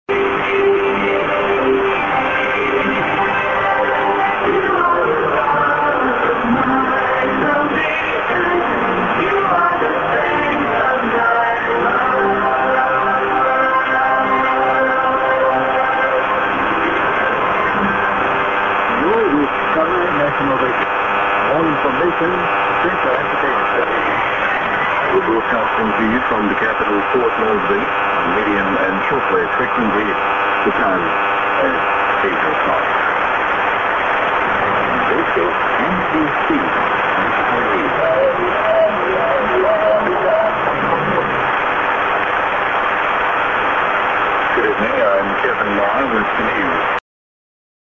->ID(man)->ID:NBC(man)->SJ->ID(man)